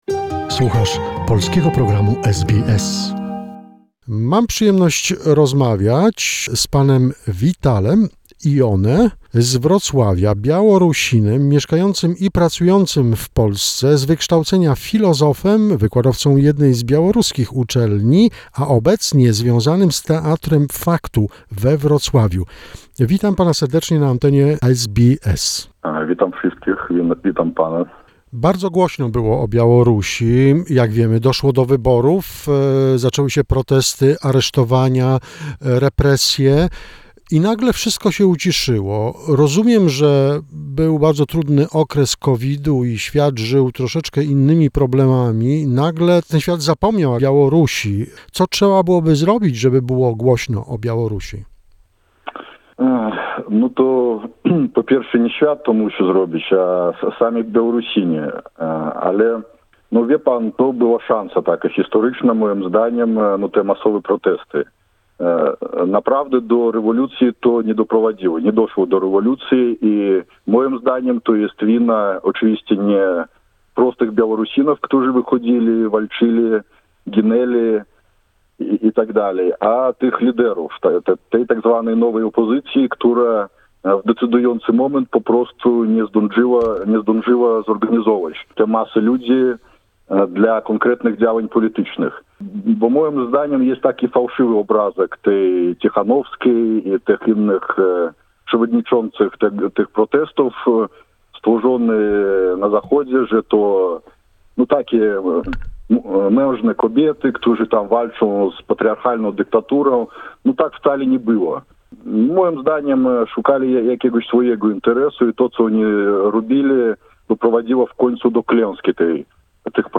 This is a final part of the interview.